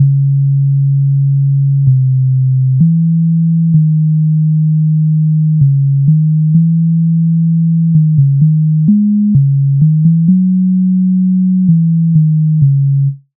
MIDI файл завантажено в тональності cis-moll